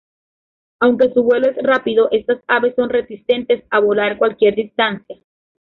/boˈlaɾ/
Mejora tu pronunciación